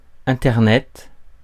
Ääntäminen
Synonyymit Internet Ääntäminen France: IPA: /ɛ̃.tɛʁ.nɛt/ Haettu sana löytyi näillä lähdekielillä: ranska Käännöksiä ei löytynyt valitulle kohdekielelle.